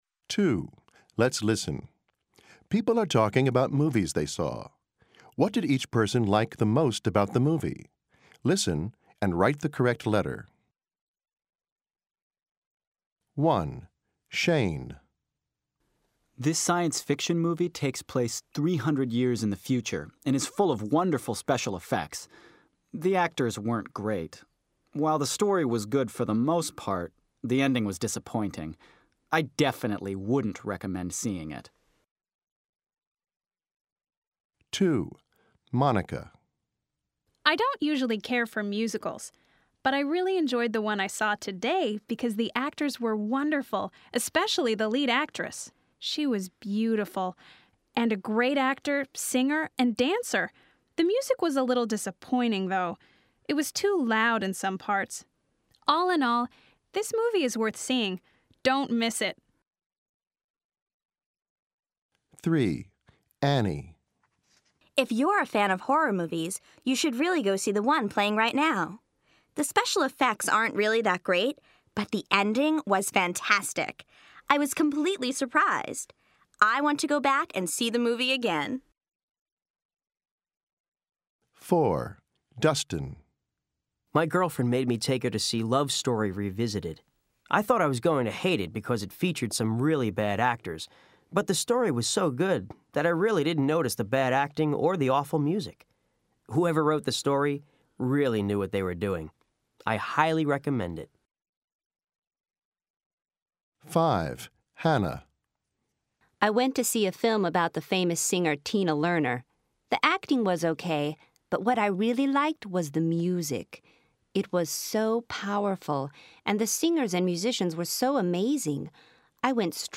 People are talking about movies they saw. What did each person like the most about the movie?